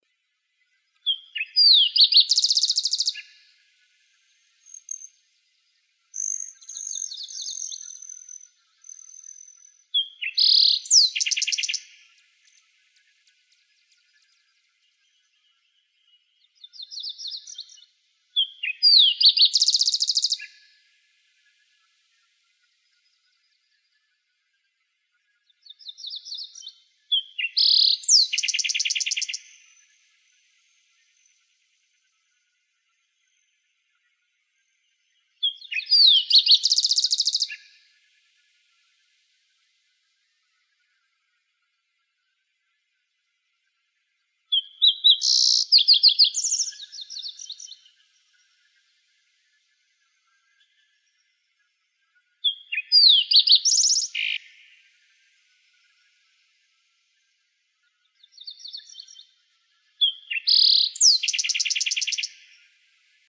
greentailedsparrow.wav